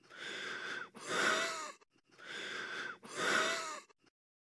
Pneumothoraxcough.mp3